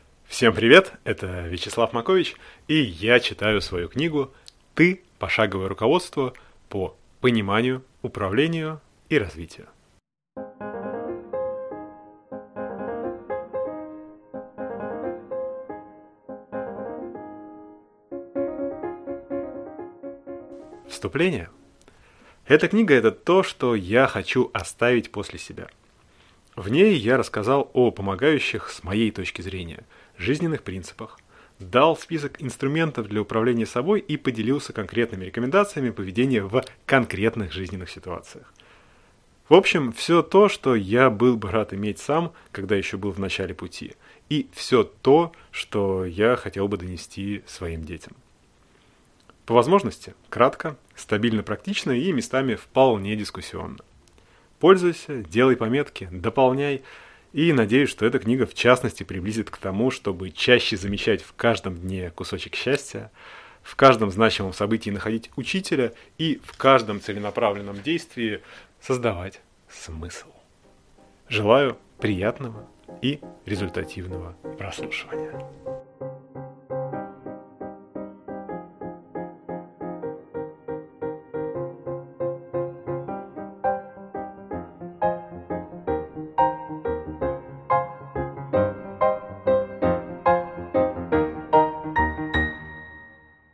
Аудиокнига Ты.